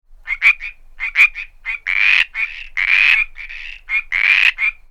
California Treefrog (or Chorus Frog) - Pseudacris cadaverina
The advertisement call of the California Treefrog is a loud quick low-pitched duck-like quacking that ends abruptly and is given repeatedly.
The following five calls were all recorded at night at close range around a small artificial desert pond (shown below) situated at the mouth of a large rocky riparian canyon in San Diego County. Male frogs were calling while floating on the water, hiding in reeds, and out in the open.
Sound This is a 5 second recording of the advertisement calls of a few frogs, including some rasping warning or encounter calls.